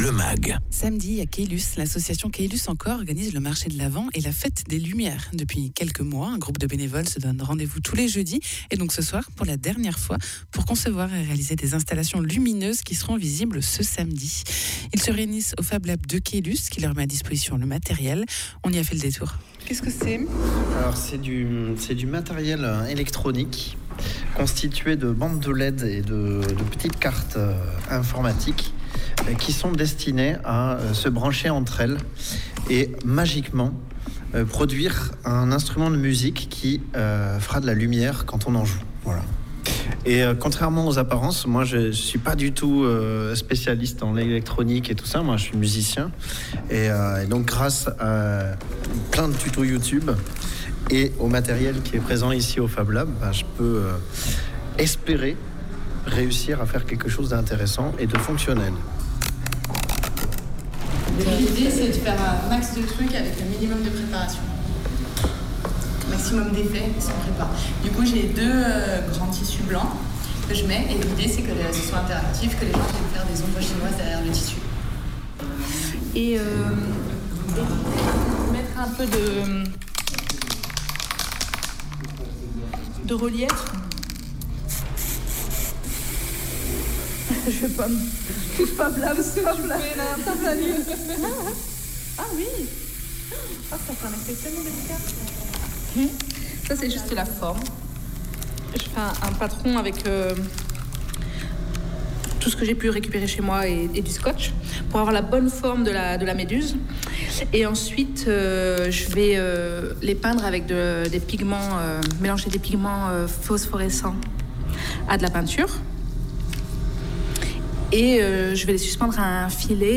Reportage à La Fabrique, fablab de Caylus, où sont confectionnés des installations lumineuses par les bénévoles de l’association Caylus en Cor pour la fête des lumières de samedi.
Interviews
Invité(s) : Les bénévoles de Caylus en Cor